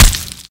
Meat Explosion.wav